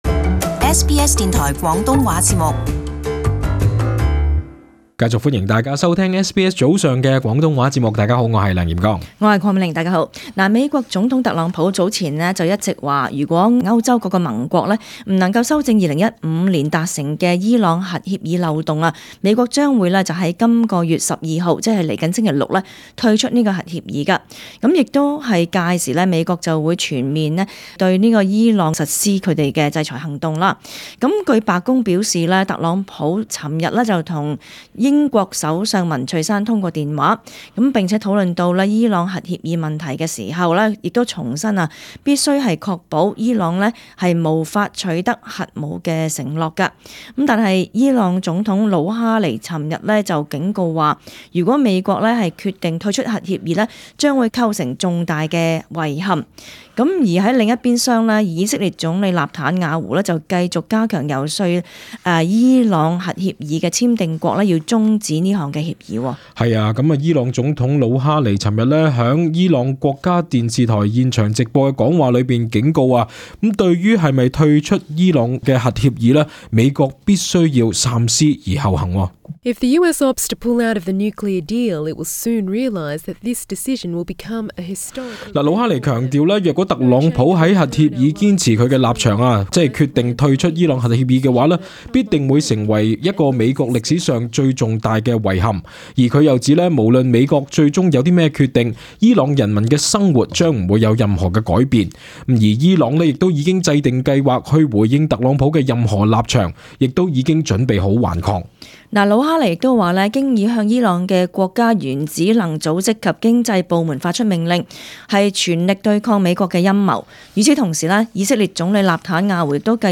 【時事報導】伊朗警告：若退出2015核協議美國必後悔